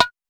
SNARE.51.NEPT.wav